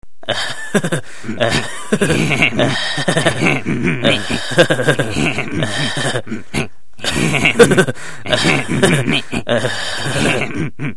Risada Beavis & Butt-Head
Famosas risadas da dupla de idiotas Beavis & Butt-Head, desenho que passava na MTV.
risada-beavis-butt-head.mp3